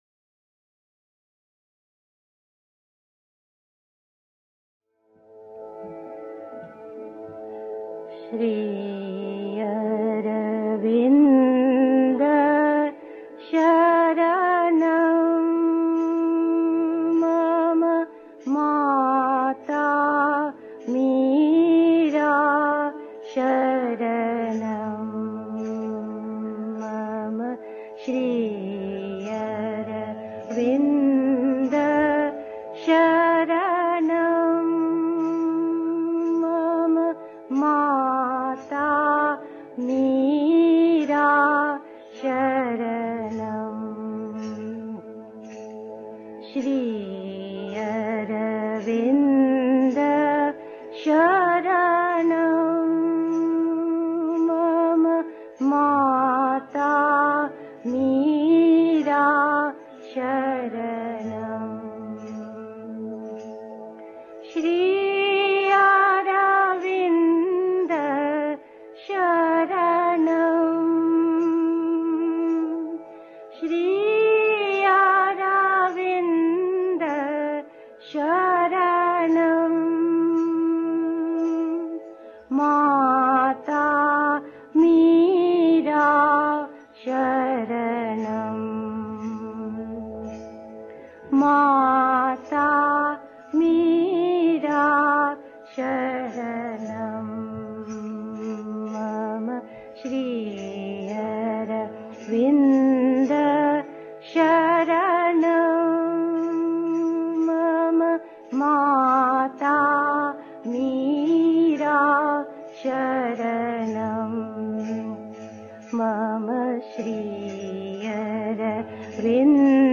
Beschreibung vor 1 Jahr 1. Einstimmung mit Musik. 2. Öffne deine Augen und sieh, was die Welt wirklich ist und was Gott (Sri Aurobindo, Thoughts and Aphorims (87.-92.)) 3. Zwölf Minuten Stille.